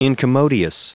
Prononciation du mot incommodious en anglais (fichier audio)